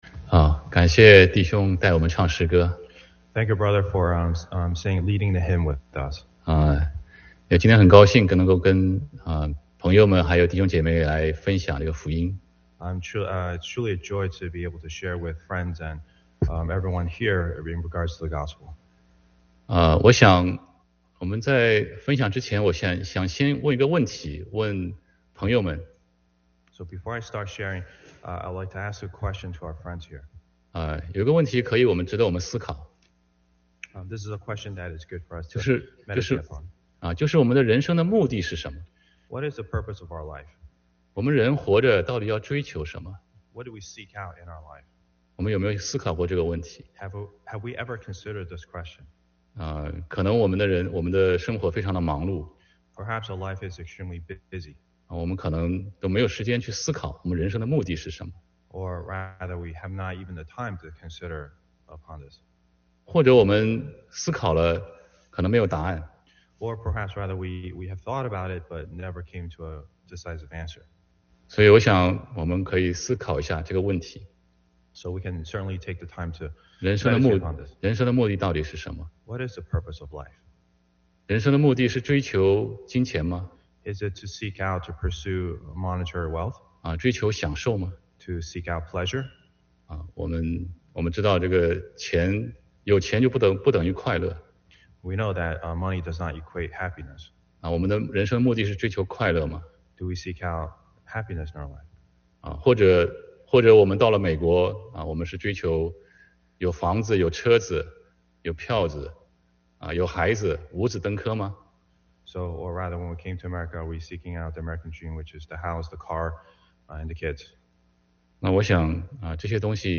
Gospel Meeting 12/19/2021 – Newark Christian Fellowship